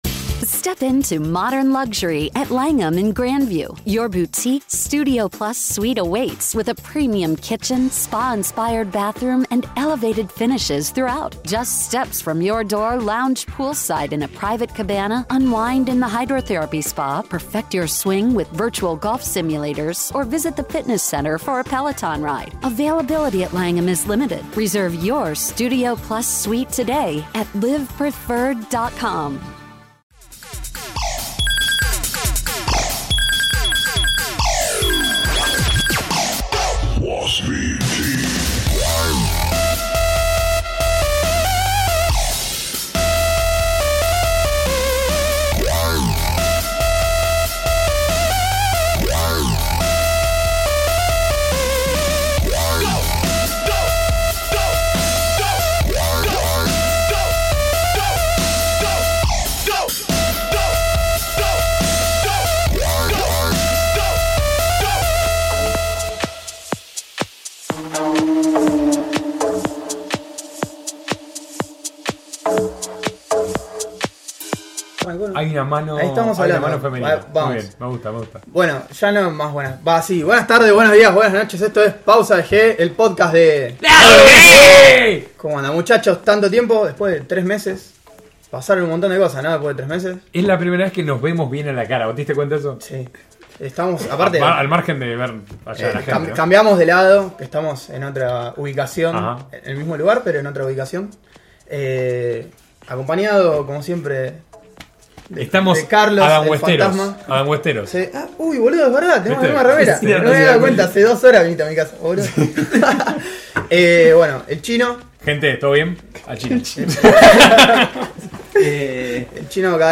Cambiamos el formato de grabación y sumamos la transmisión en vivo vía YouTube. Nota: Pedimos disculpas por los problemas en la transmisión de video, el audio se escucha perfectamente.